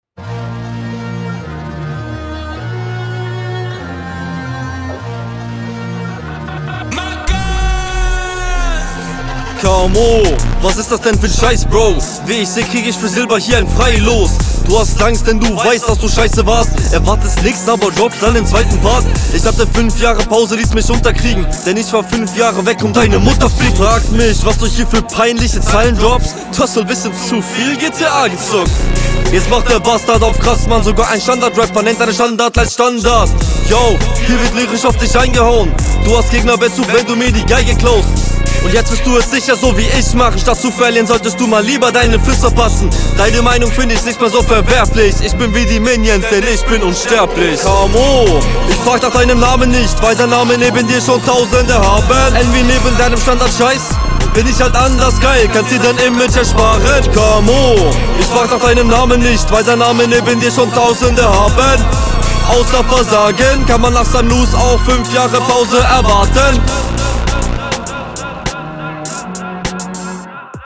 Wirkt an manchen stellen n bisschen überfordert mit dem Beat.